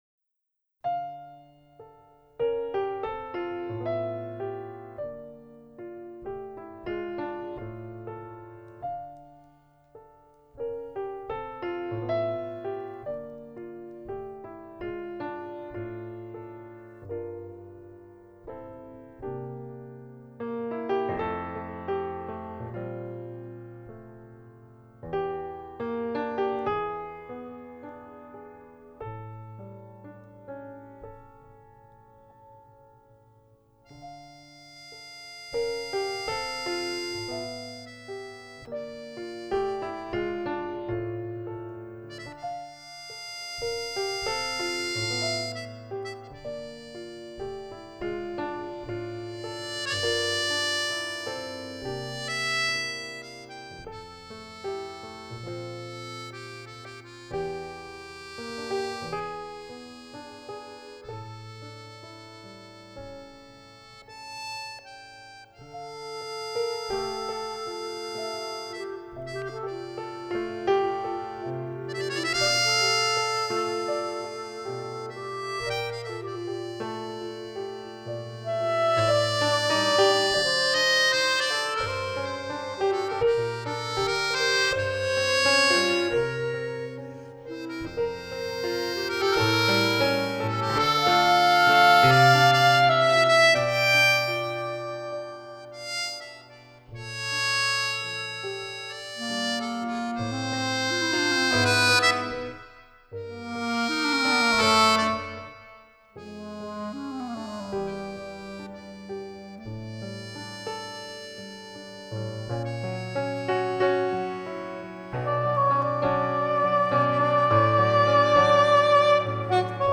pianoforte
sax e clarinetto
fisarmonica